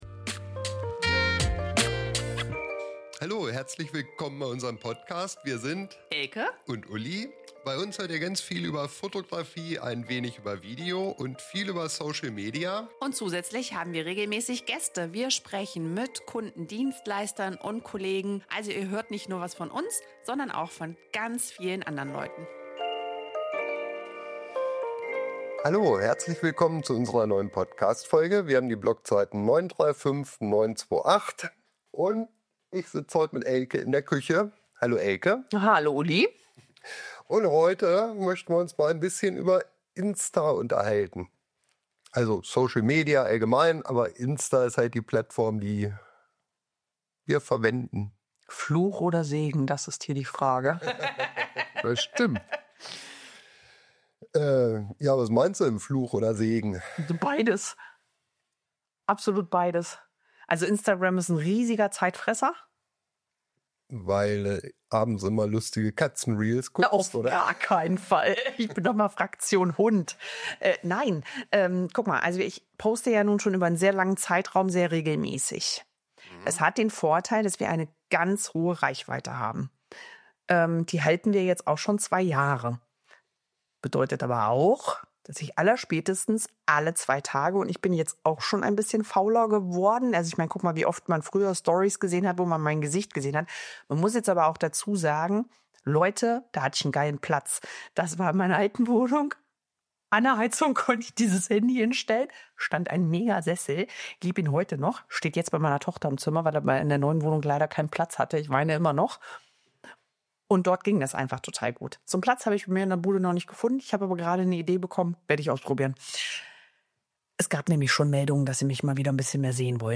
Hier kannst du mehr erfahren: In dieser Podcastfolge diskutieren wir in entspannter Küchenatmosphäre über Instagram und Social Media.